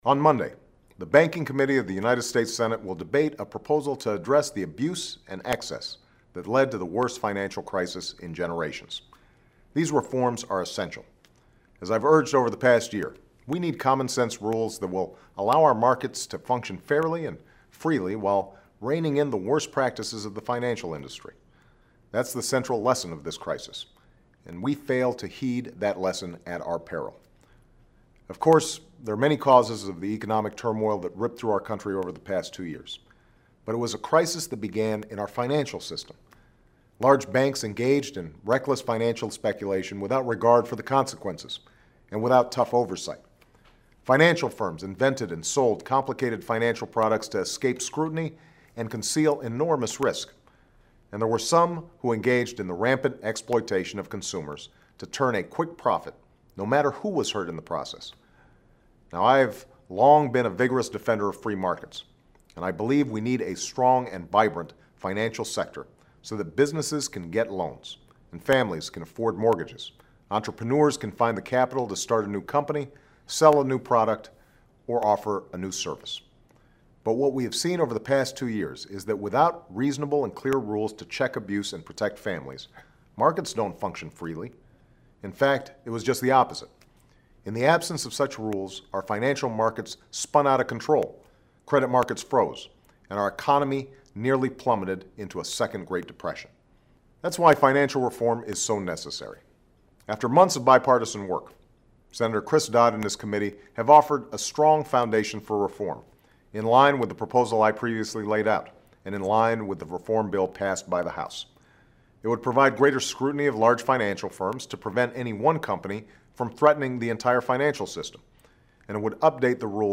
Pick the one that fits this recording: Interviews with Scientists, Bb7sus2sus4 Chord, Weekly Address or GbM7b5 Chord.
Weekly Address